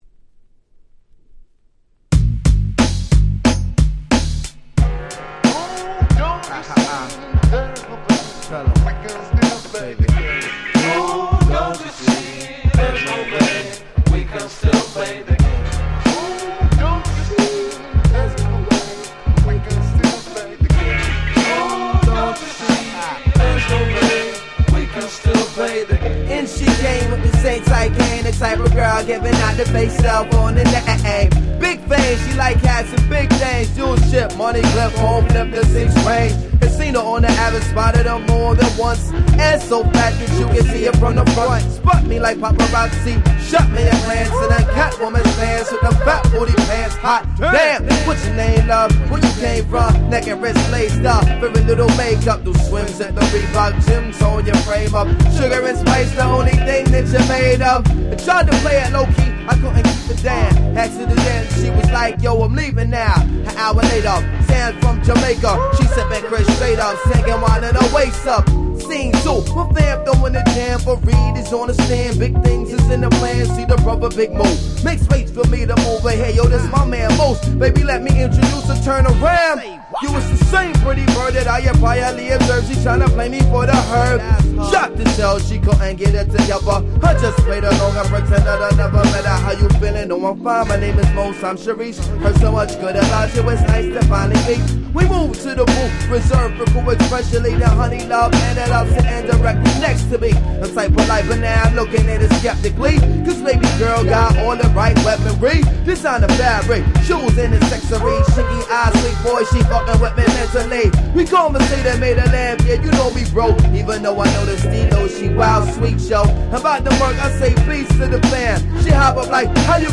ブーンバップ Boom Bap